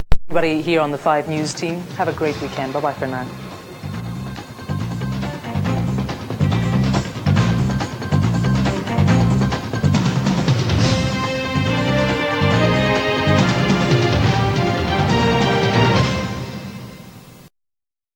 Closing Titles